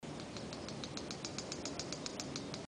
Wren-like Rushbird (Phleocryptes melanops)
Life Stage: Adult
Location or protected area: Reserva Natural del Pilar
Condition: Wild
Certainty: Observed, Recorded vocal